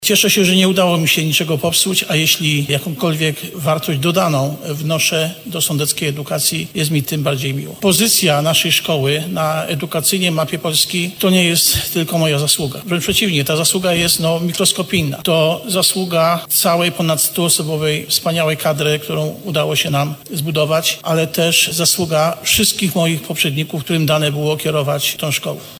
Podczas wystąpienia na sesji rady miasta wypowiadał się ze skromnością.